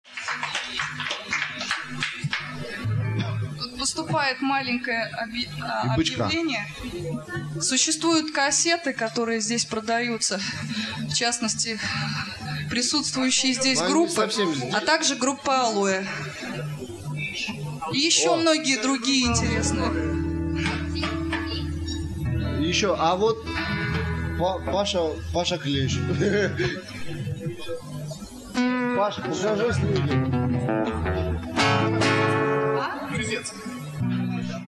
Концерт 14 февраля